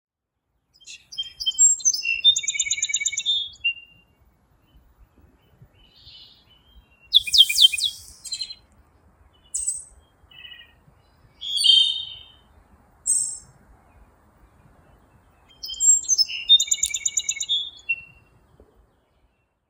eveningsong.wav